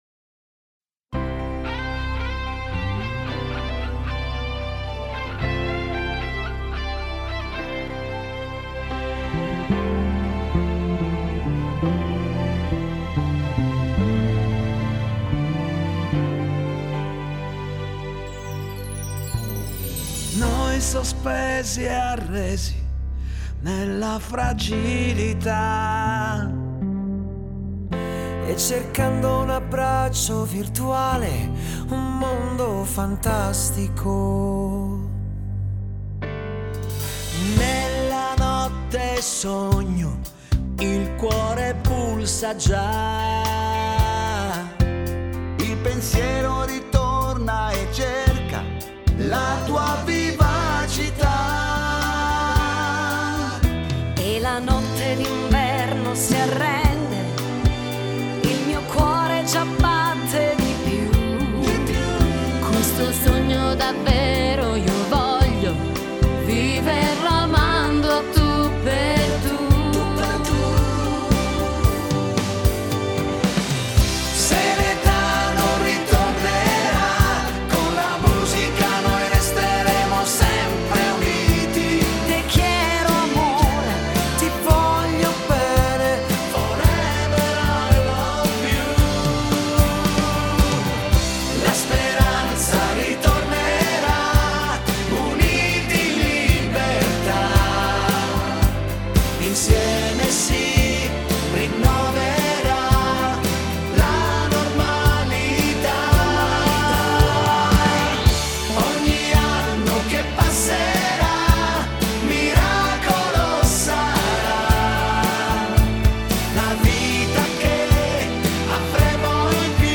Chitarre
Pianoforte
Basso
Batteria